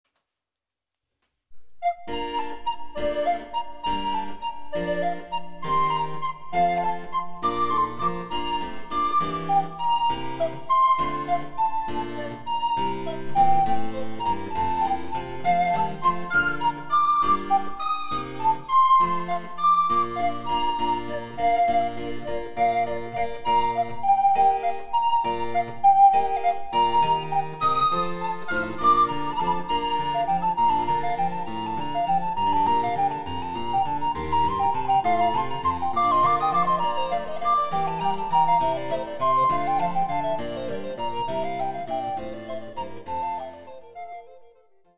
Recorder I とRecorder II がユニゾンで開始する主要主題は力強くて躍動感があります。